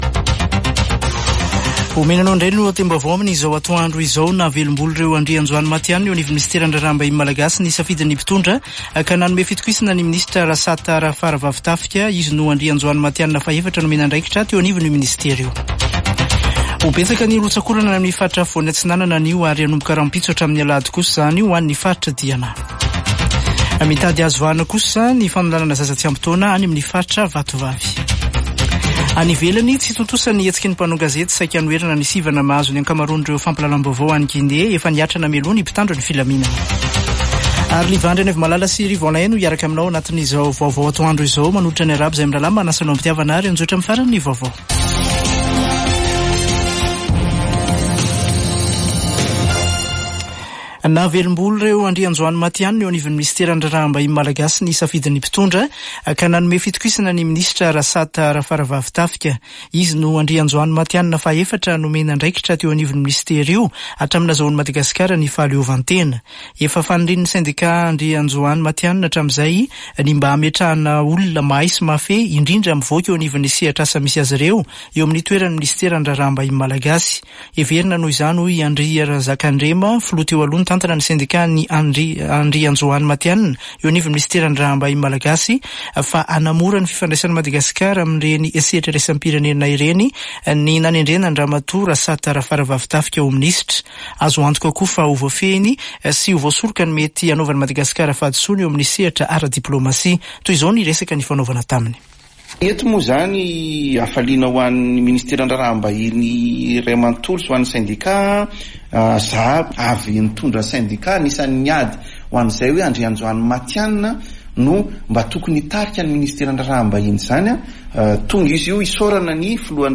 [Vaovao antoandro] Zoma 19 janoary 2024